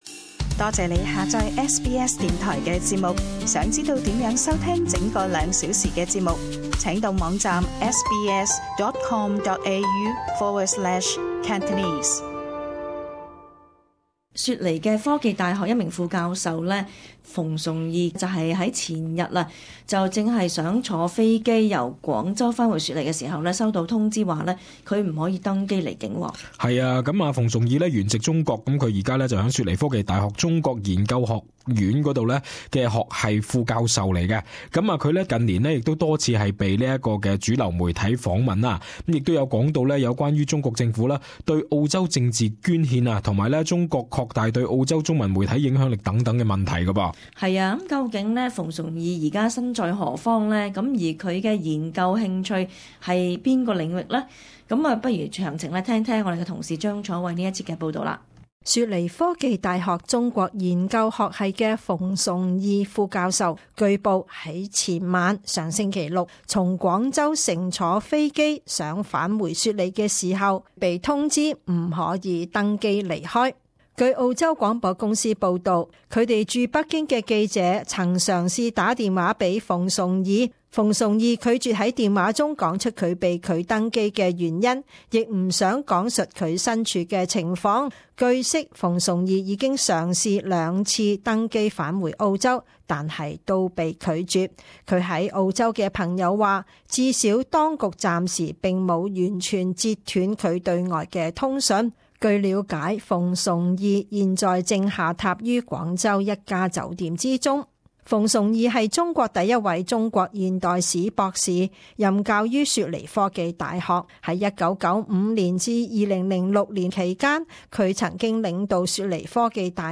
【時事報導】